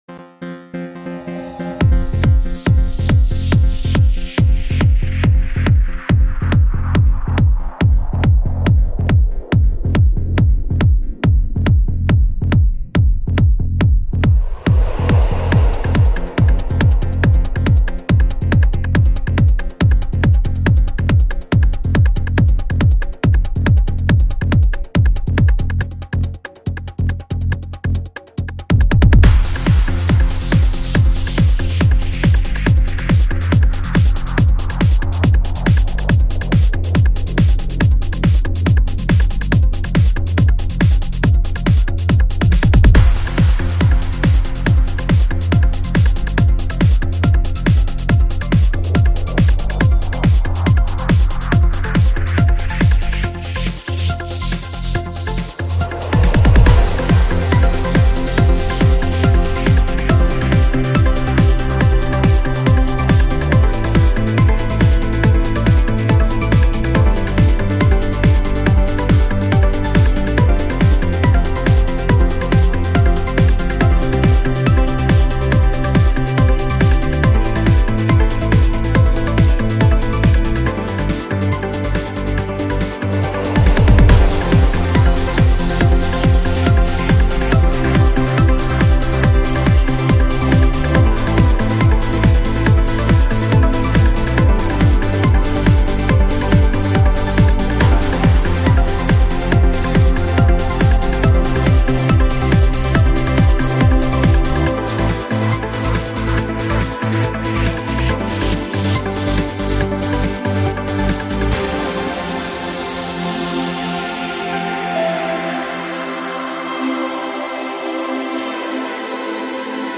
It is full of joy and fun...
GENRE: Trance